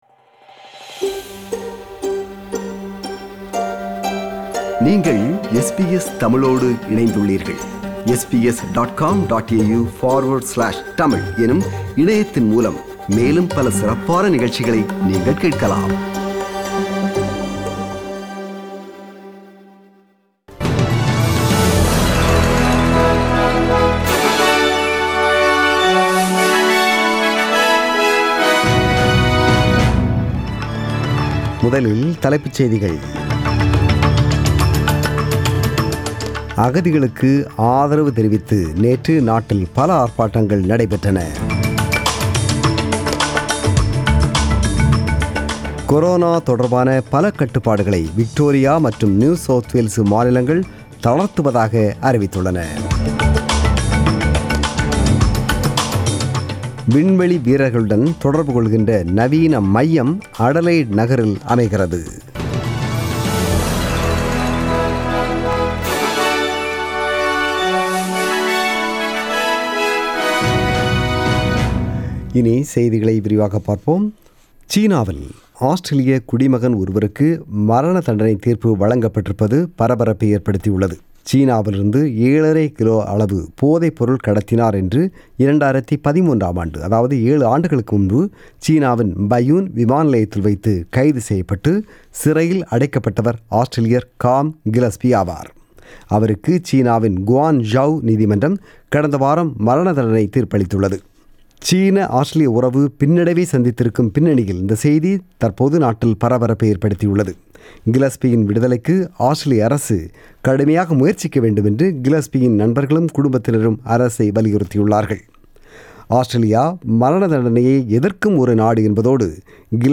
The news bulletin was broadcasted on 14 June 2020 (Sunday) at 8pm.